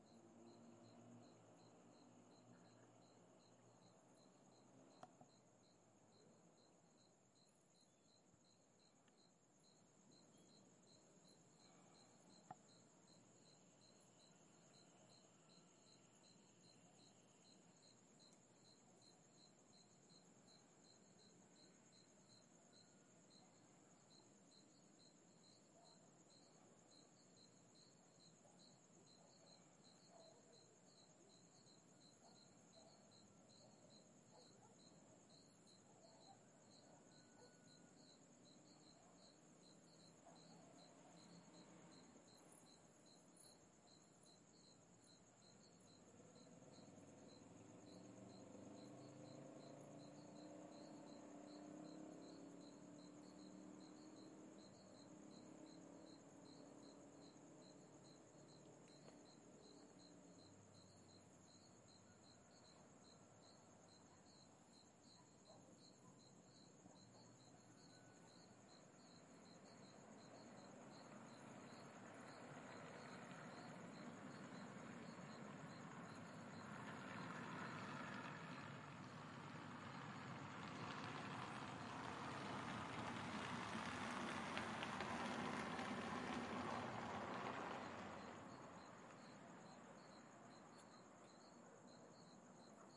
Da janela do meu quarto，com aparelhos calibrados，analisava o ambiente do campo e todos os sons que ele me proporcionava。 Numhoráriomaiscalmo，ruajávazia，veículoscomeçamapassar，cachorros brincam no meio da grama，sons bem noturnos e calmos，compresençadegrilos sapos。
Tag: cachorros AMBIENTE 声景 TRANSPORTES 环境 晚报 字段 氛围 汽车 坎波 SAPOS noght grilos